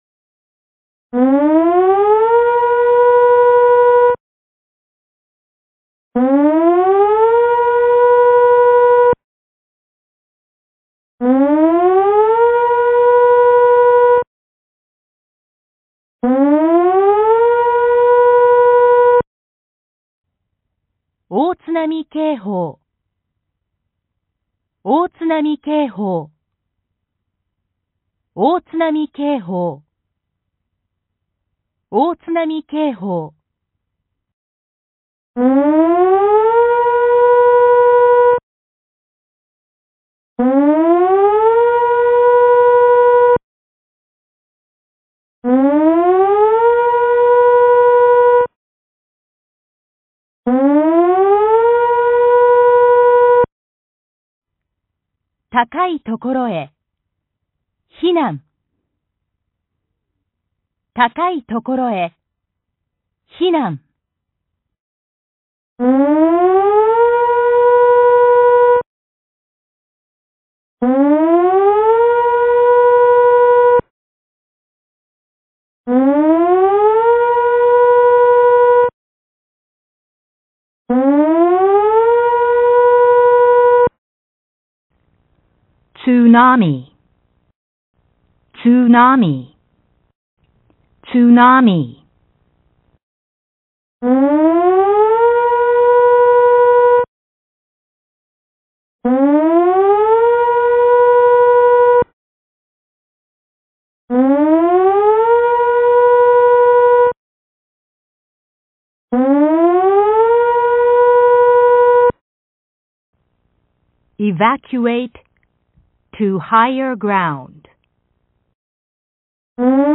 根據氣象廳發布的大海嘯警報、海嘯警報、海嘯警報,設置的室外揚聲器將自動播放海嘯警報、避難勸告等緊急資訊。
1. 警報(4次)
約3秒(約2秒秋)
※播出1～8 3套。另外,各套餐還播放了不同語言(英語、華語、韓語)催促避難的語言。
※9的汽笛只在第3局播放。
大海嘯警報音源(3m<海嘯的預測高度≤10m)(音樂文件(MP3):5617KB)